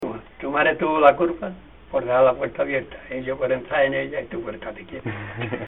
Materia / geográfico / evento: Canciones eróticas Icono con lupa
Moraleda de Zafayona (Granada) Icono con lupa
Secciones - Biblioteca de Voces - Cultura oral